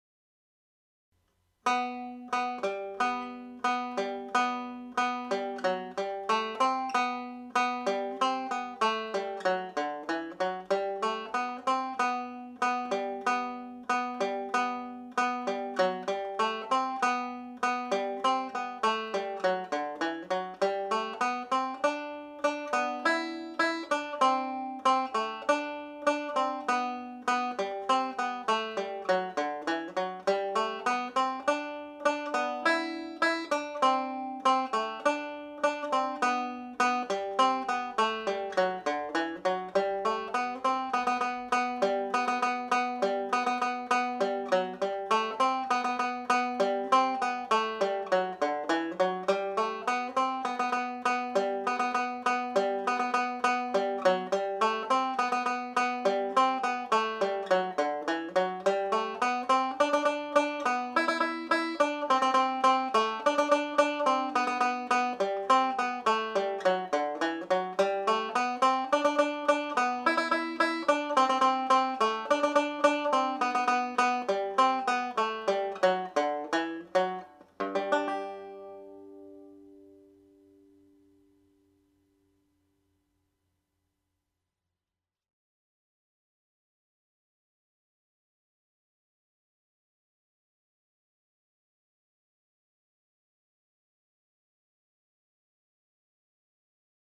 • banjo scale
The Fairy Dance reel played a little faster with single notes first time around then with triplets second time around.